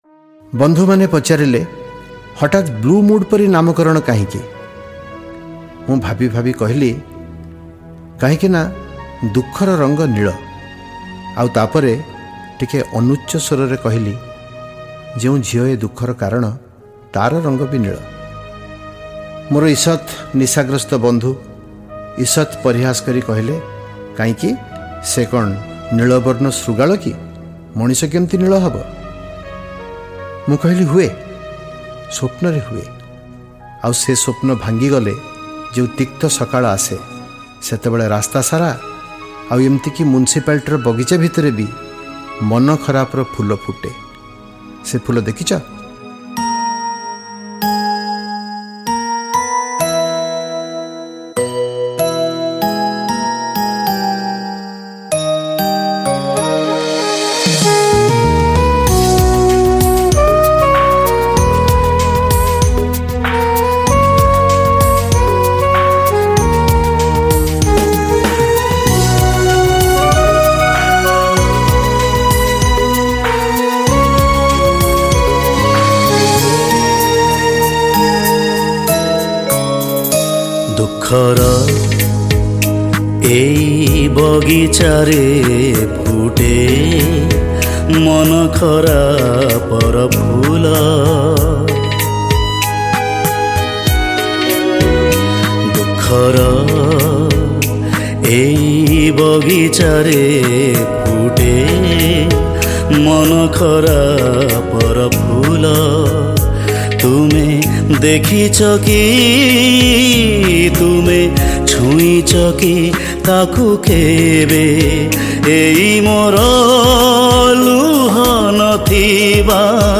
Category: Blue Mood